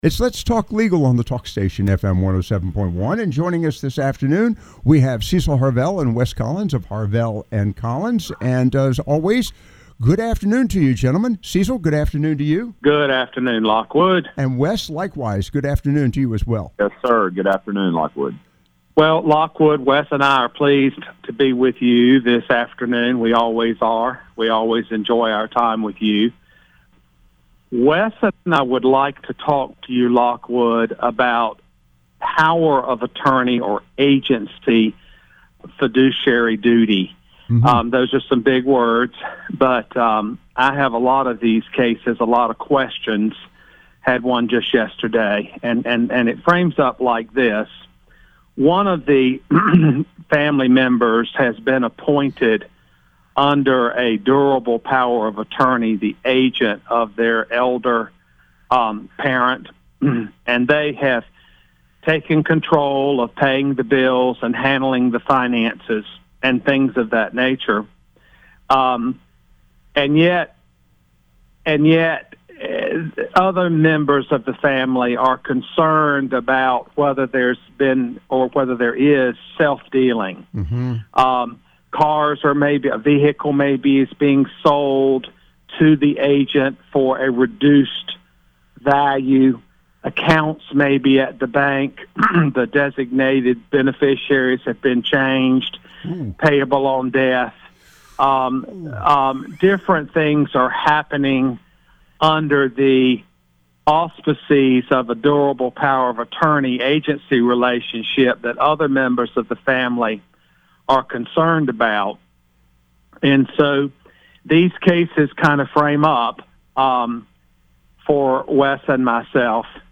The conversation highlighted the importance of transparency and communication among family members to mitigate potential conflicts regarding estate management.